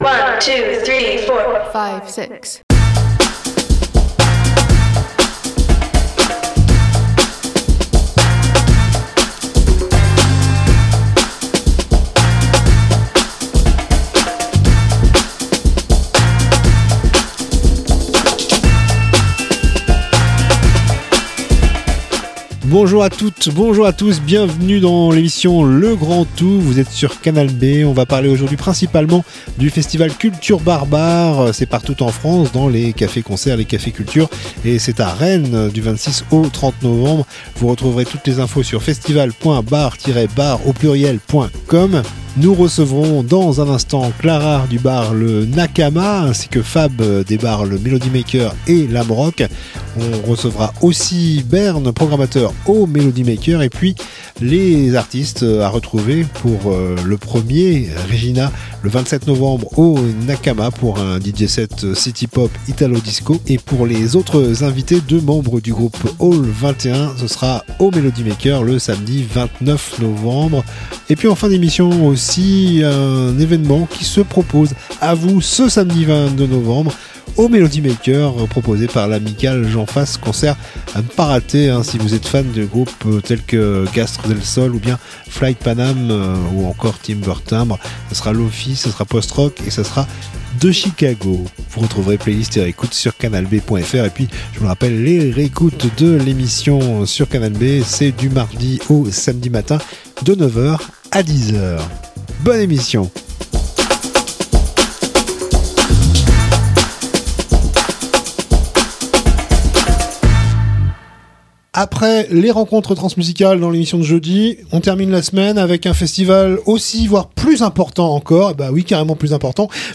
itv musique / infos-concerts